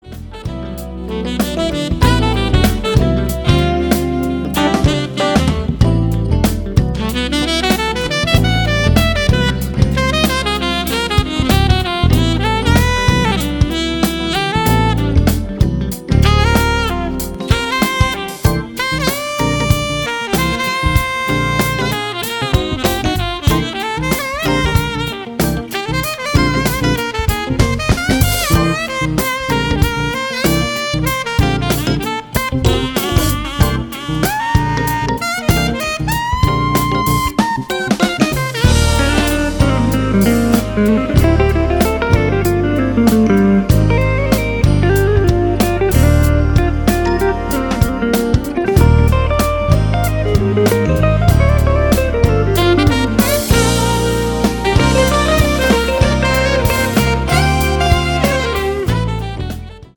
It is fusion
funky tunes
riff laden affair